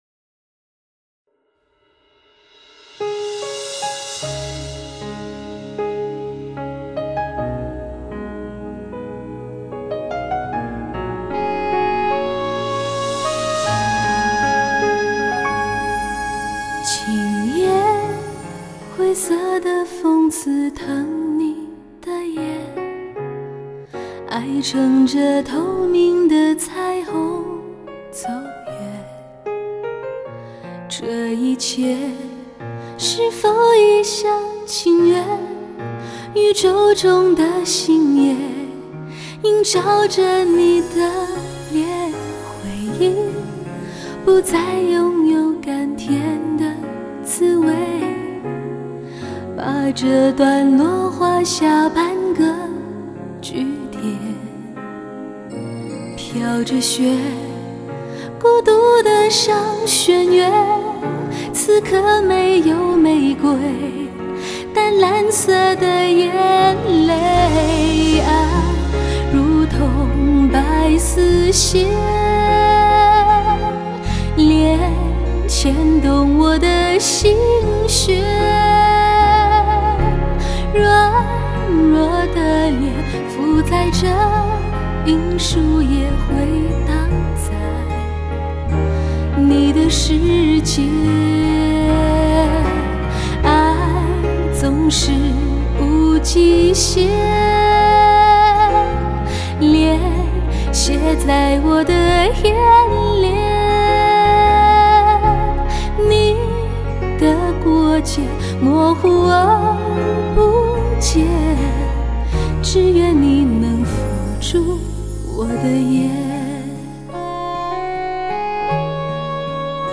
乐器的分隔度、定位良好，弦乐群华丽高贵，钢琴的形态和位置浮现，琴键的触音粒粒可闻。
甜美的歌聲.